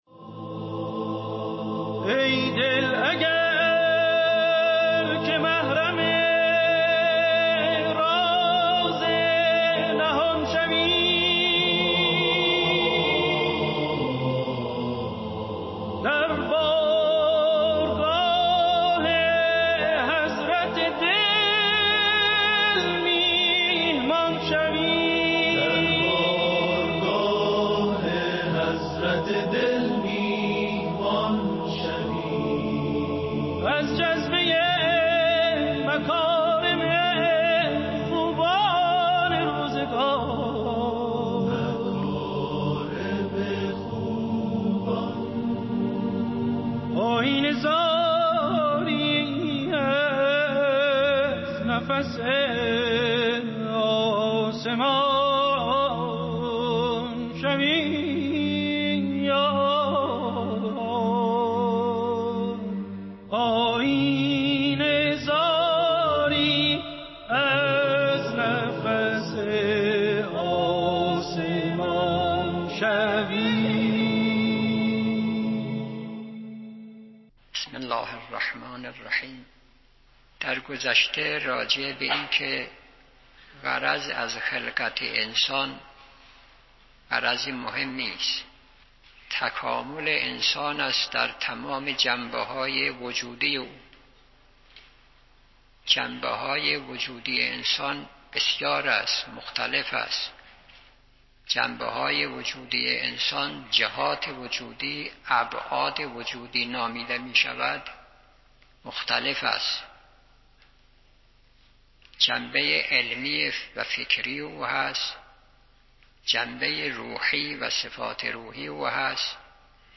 درس اخلاق | هر بزرگی بزرگوار نیست